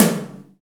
Index of /90_sSampleCDs/Roland - Rhythm Section/KIT_Drum Kits 8/KIT_Reverb Kit
TOM GRINDE06.wav